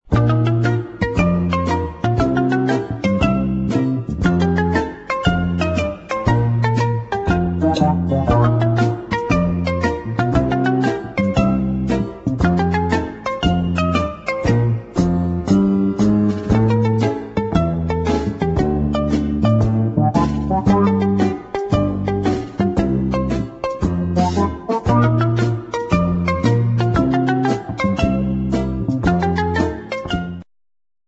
funny medium instr.